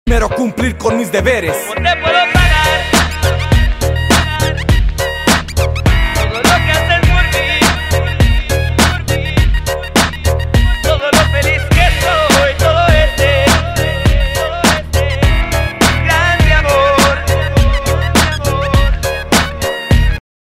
• Качество: 128, Stereo
remix